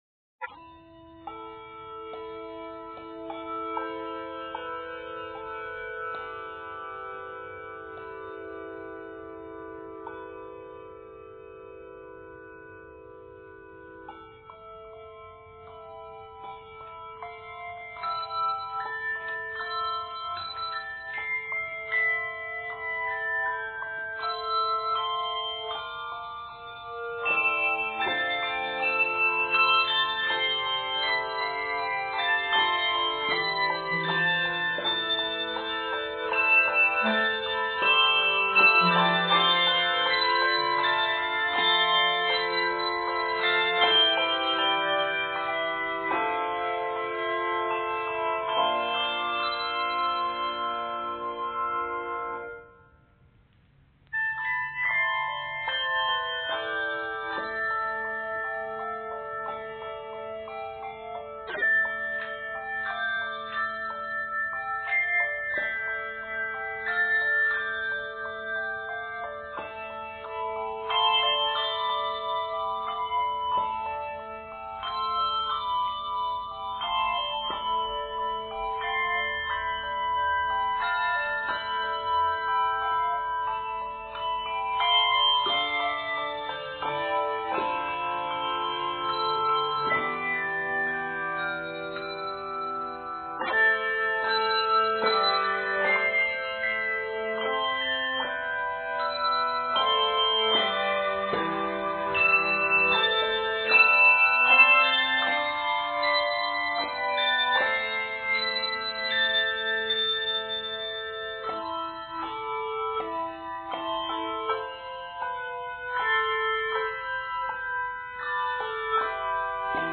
tender work